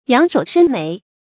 仰首伸眉 注音： ㄧㄤˇ ㄕㄡˇ ㄕㄣ ㄇㄟˊ 讀音讀法： 意思解釋： 仰首：仰起頭來；伸眉：舒展眉頭。